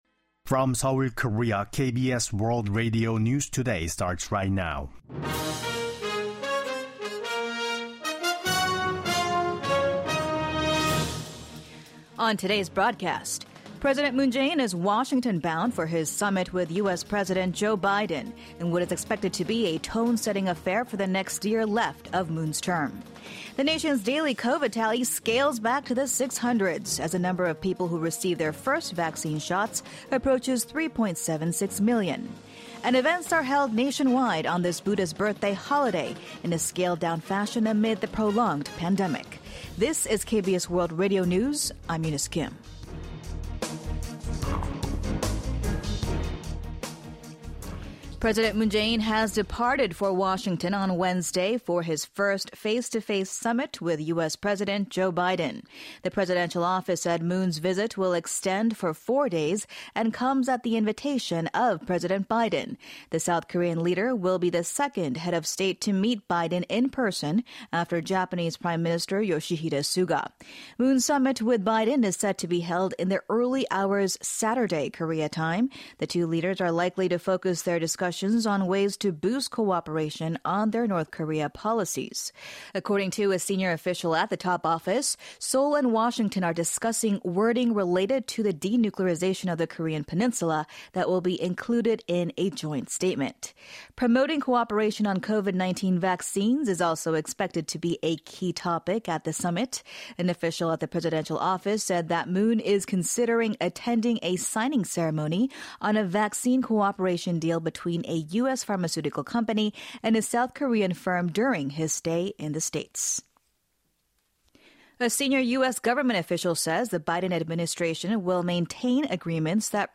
The latest news from home and abroad, with a close eye on Northeast Asia and the Korean Peninsula in particular … continue reading 563 επεισόδια # South Korea # KBS WORLD Radio # International News # News